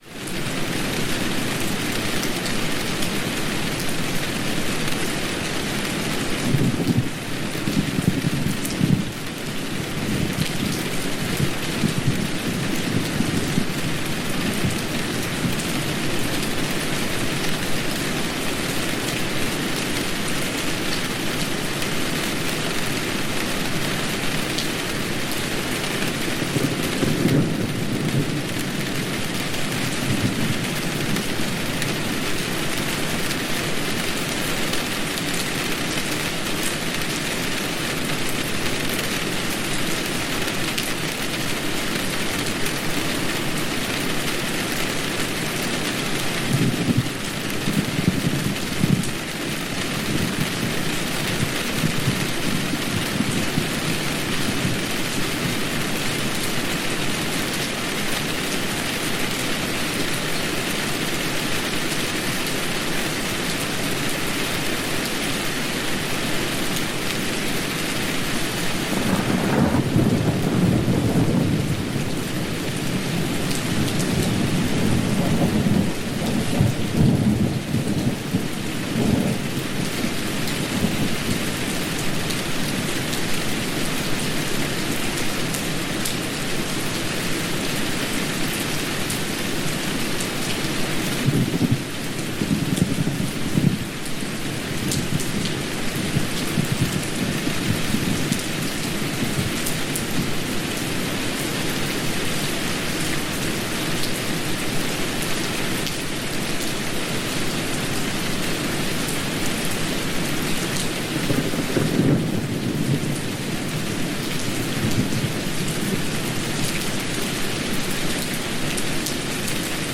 Lluvia sobre la Tienda para un Sueño Sereno y Restaurador
En Descanso Con Lluvia, todos los anuncios viven al inicio de cada episodio porque entendemos lo importante que es mantener intacto ese espacio en el que tu mente empieza a relajarse.
Sonido de Lluvia, Lluvia Relajante, Lluvia Suave, Lluvia Nocturna